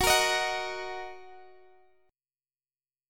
Listen to F#6 strummed